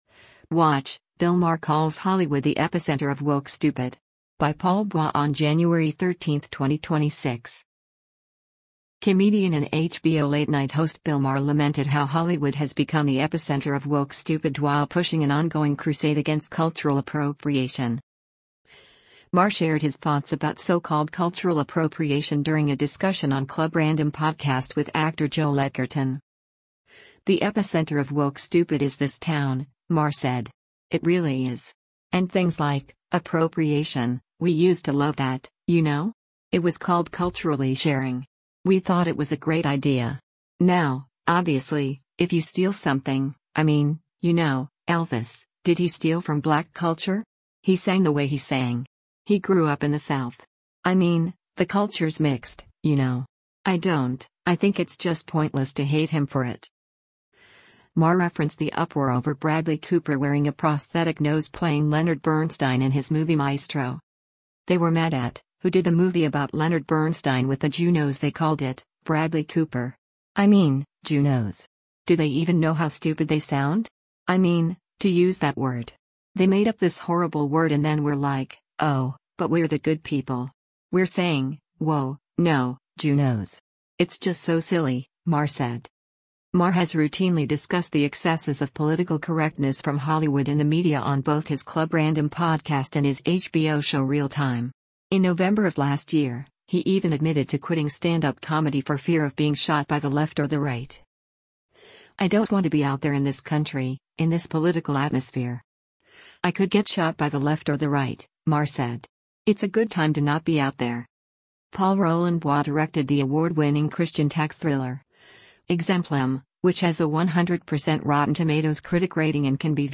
Maher shared his thoughts about so-called “cultural appropriation” during a discussion on Club Random podcast with actor Joel Edgerton.